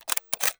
CAMERA_DSLR_Shutter_01_mono.wav